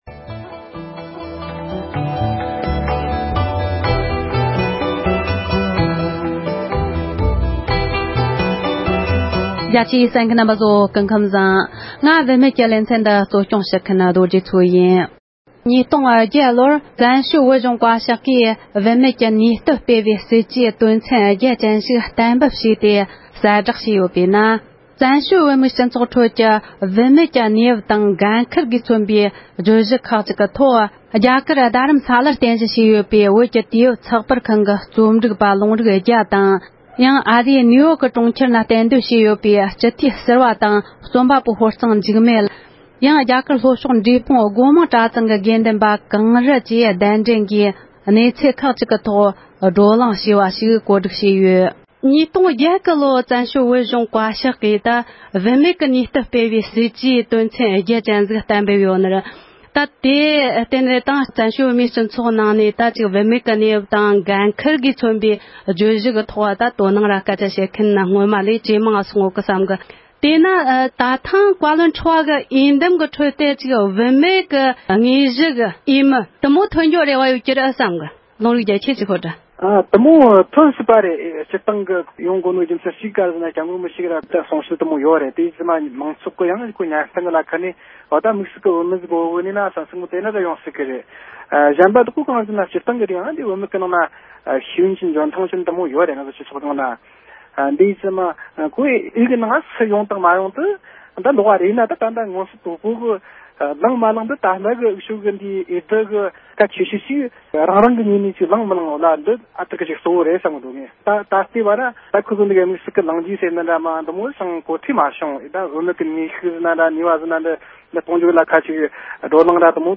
བཙན་བྱོལ་བོད་གཞུང་བཀའ་ཤག་གི་བུད་མེད་ཀྱི་ནུས་སྟོབས་གོང་འཕེལ་གཏང་རྒྱུའི་སྲིད་བྱུས་དང་མ་འོངས་པའི་འོས་བསྡུ་སྐོར་བགྲོ་གླེང༌།
སྒྲ་ལྡན་གསར་འགྱུར།